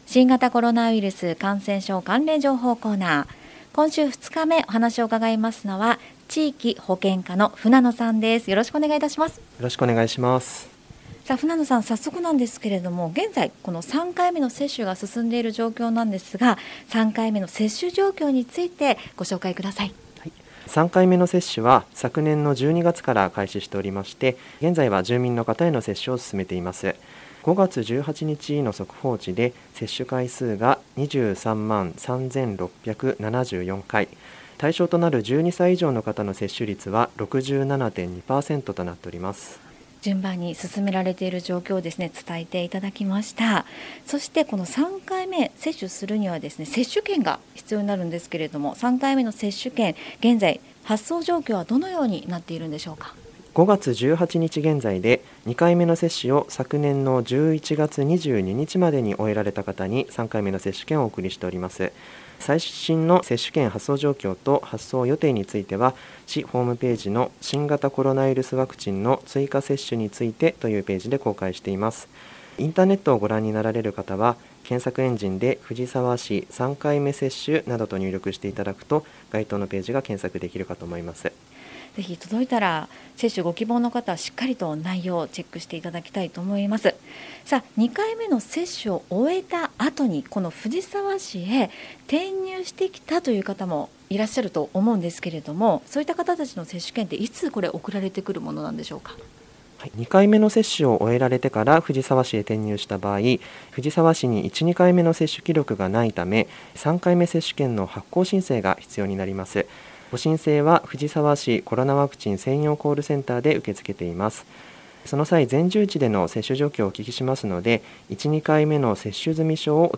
令和4年度に市の広報番組ハミングふじさわで放送された「新型コロナウイルス関連情報」のアーカイブを音声にてご紹介いたします。